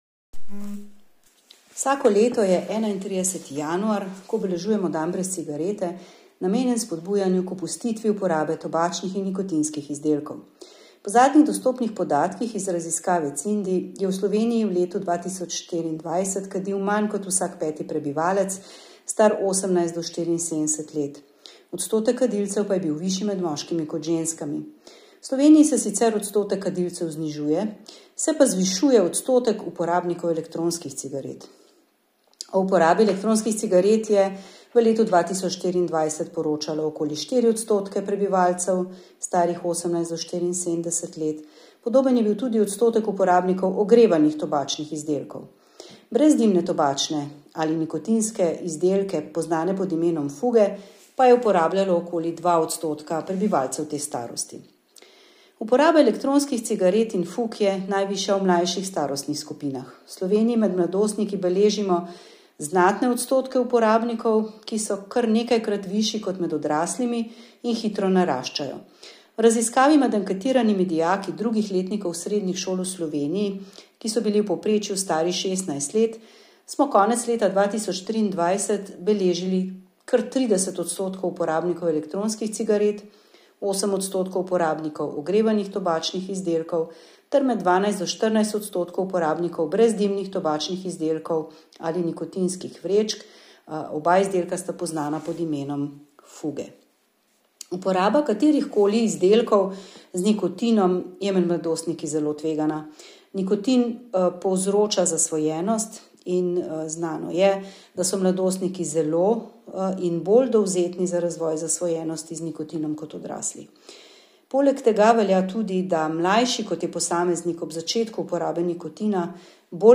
Avdio izjave: